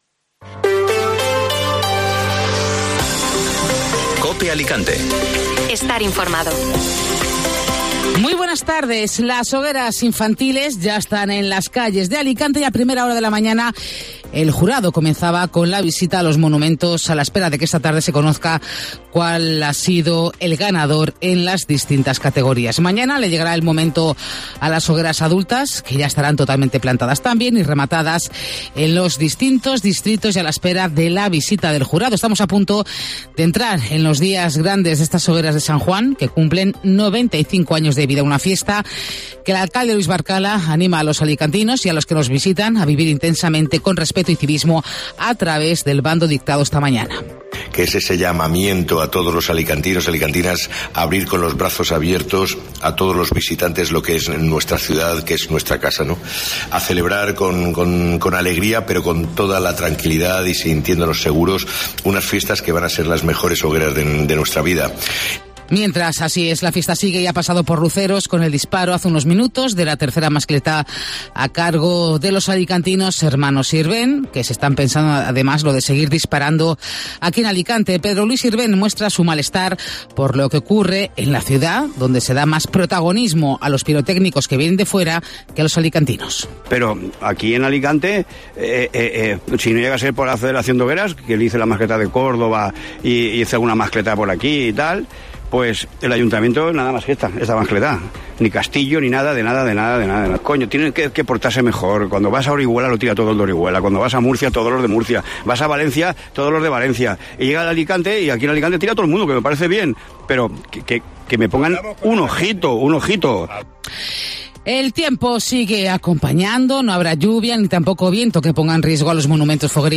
Informativo Mediodía Cope Alicante ( Martes 20 de junio)